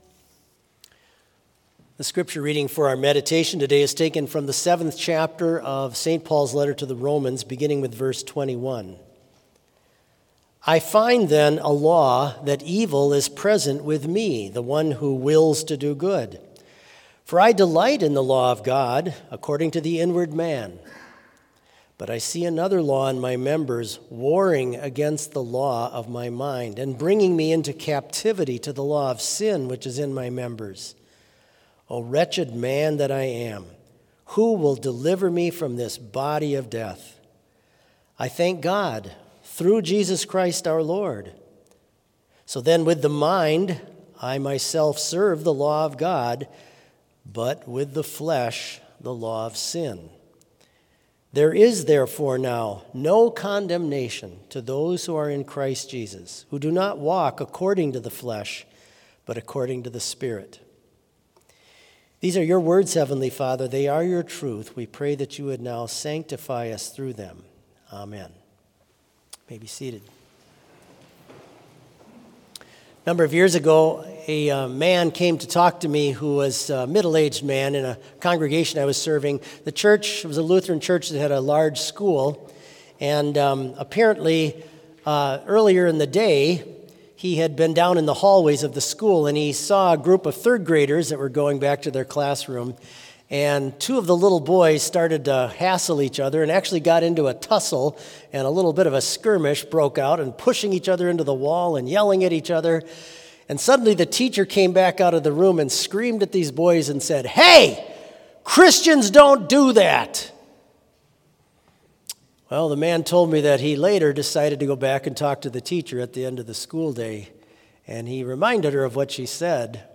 Complete service audio for Chapel - March 2, 2023